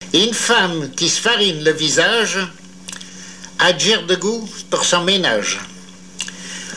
Langue Patois local
Genre dicton
émission La fin de la Rabinaïe sur Alouette